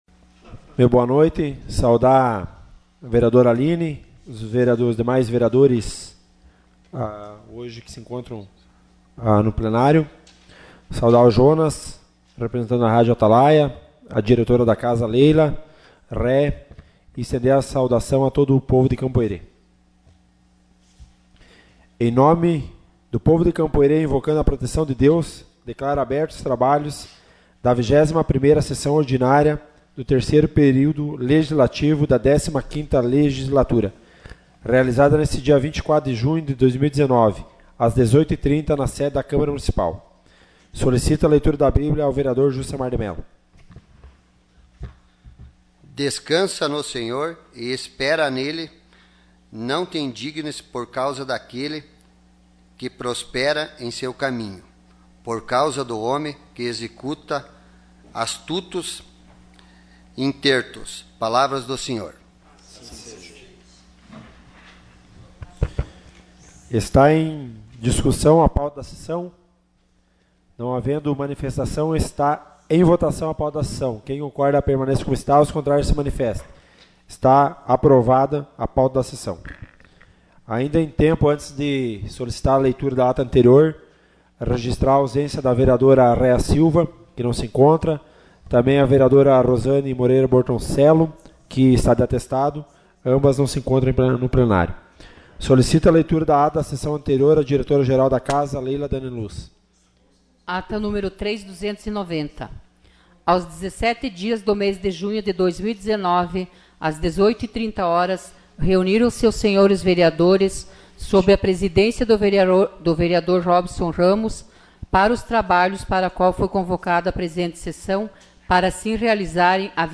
Sessão Ordinária 24 de junho de 2019.